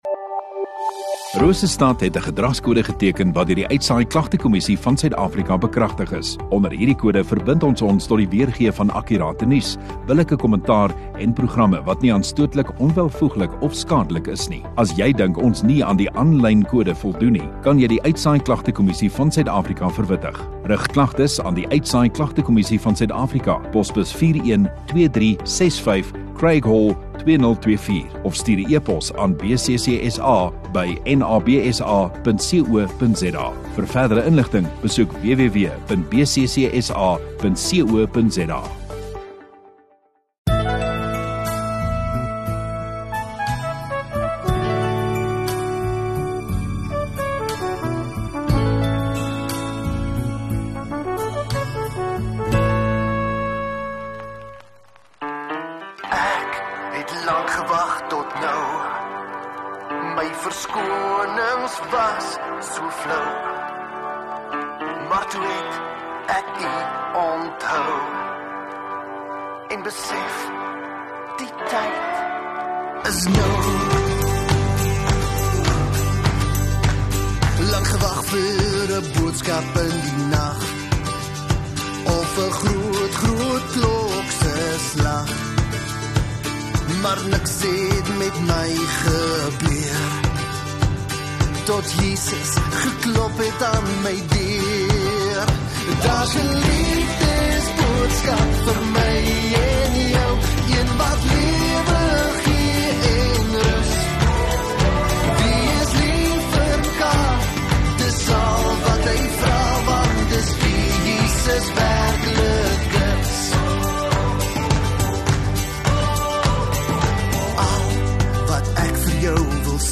26 May Sondagoggend Erediens